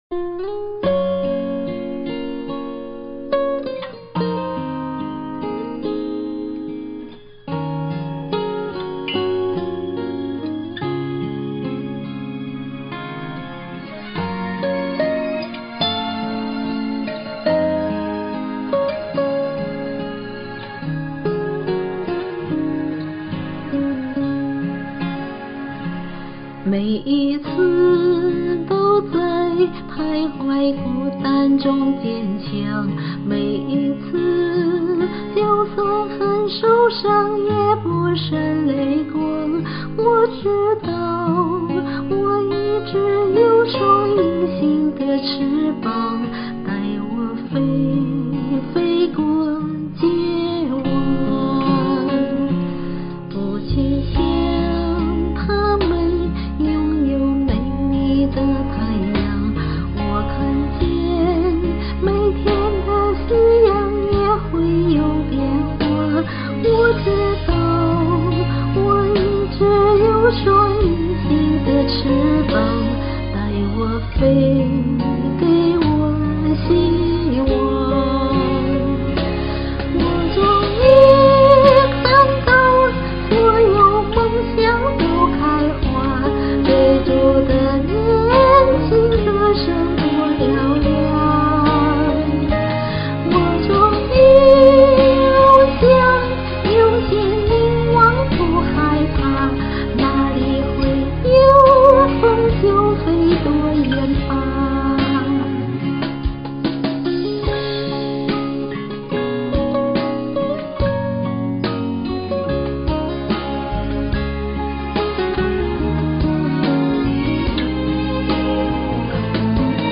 好柔美的歌声，只一遍就唱得这么好，佩服！